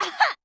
fall_1.wav